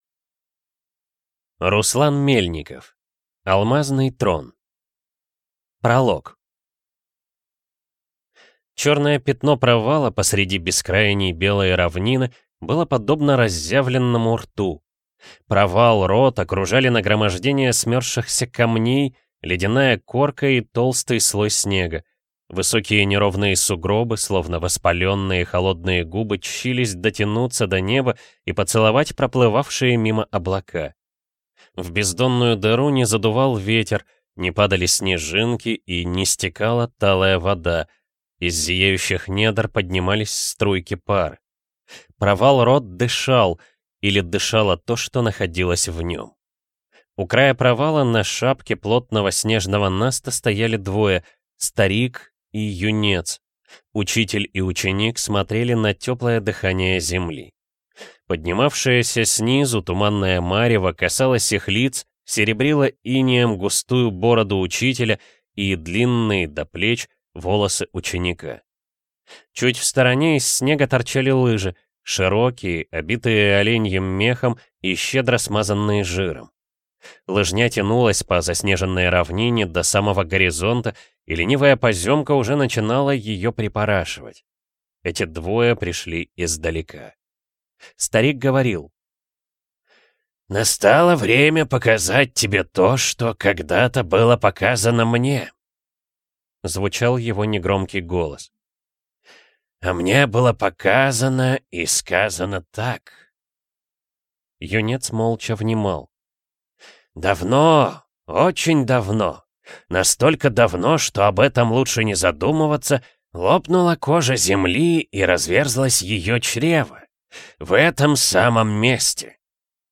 Aудиокнига Алмазный трон
Читает аудиокнигу